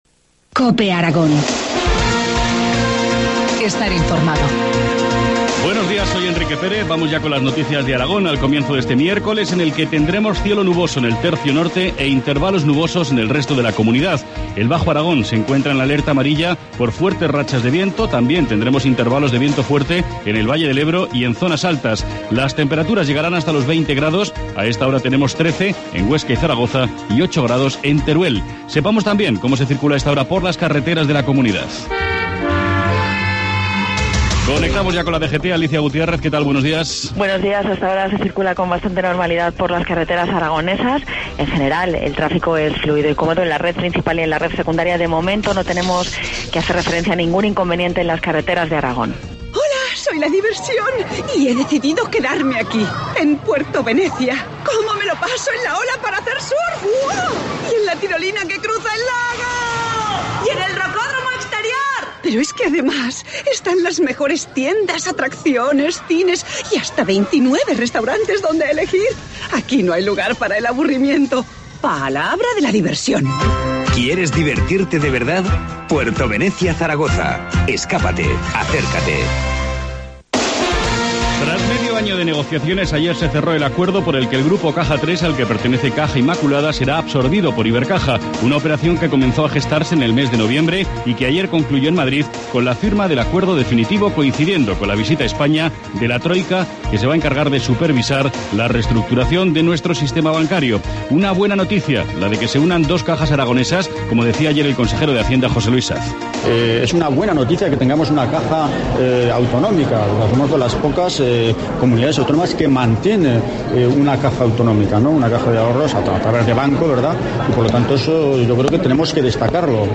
Informativo matinal, miércoles 22 de mayo, 7.25 horas